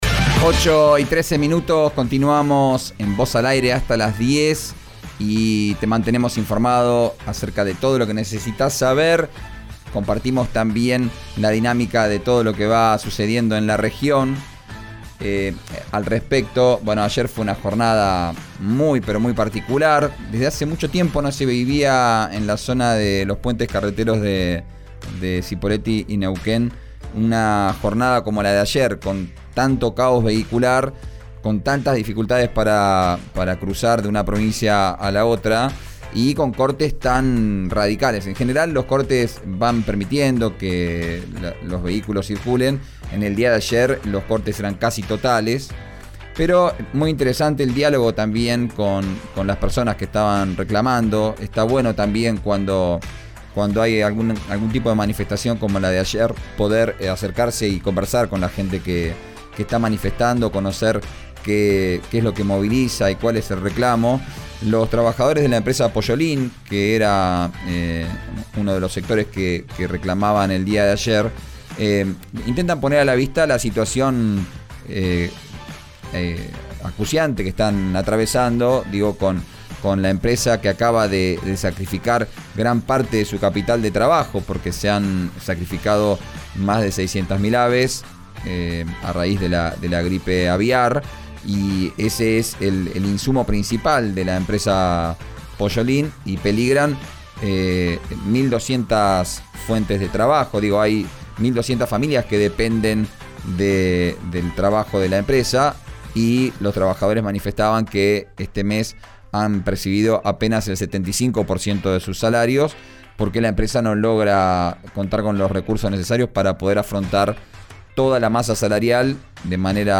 El secretario de ganadería de Río Negro habló al respecto en RÍO NEGRO RADIO.